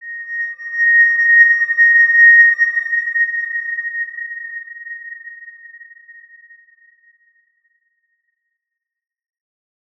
X_Windwistle-A#5-pp.wav